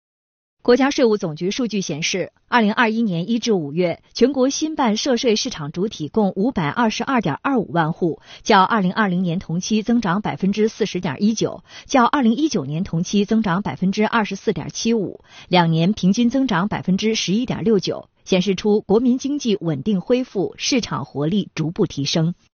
视频来源：央视《新闻联播》